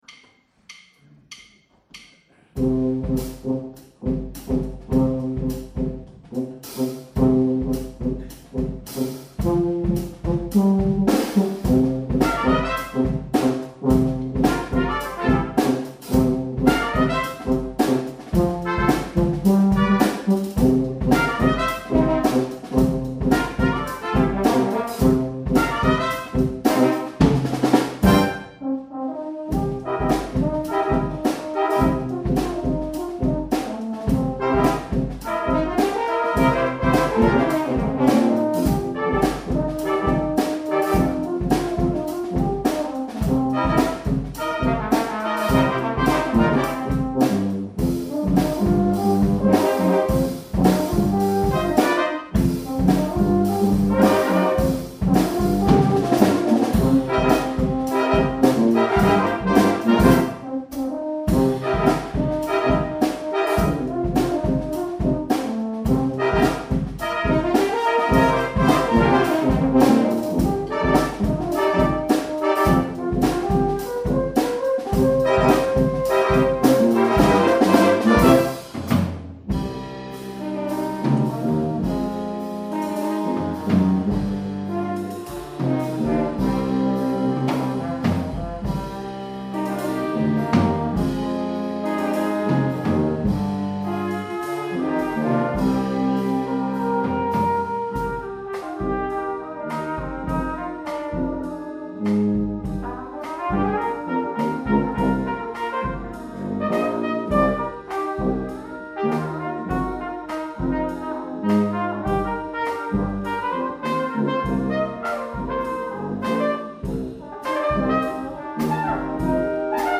Alphorn, Brassquintett & Drum Set (6 Minuten)